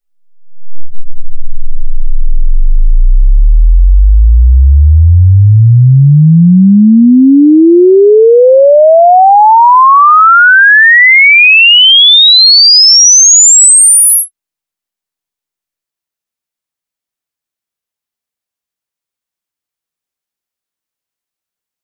link to the forward sweep file.